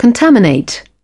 Transcription and pronunciation of the word "contaminate" in British and American variants.